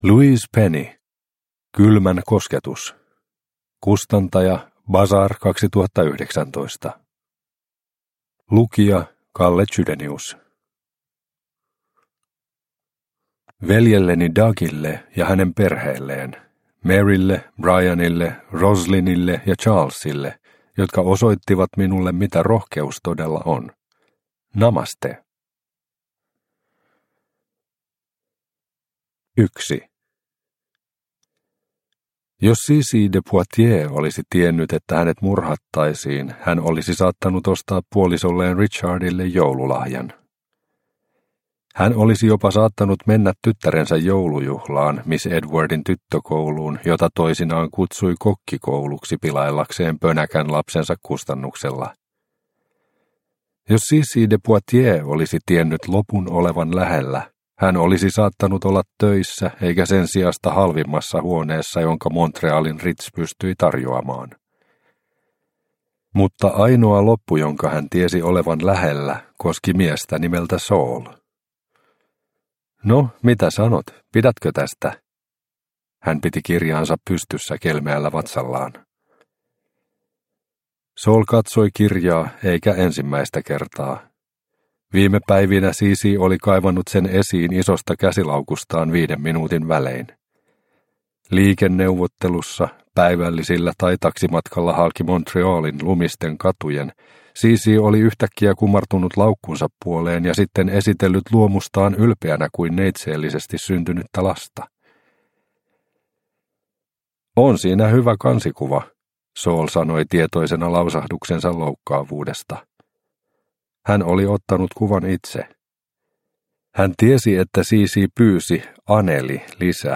Kylmän kosketus – Ljudbok – Laddas ner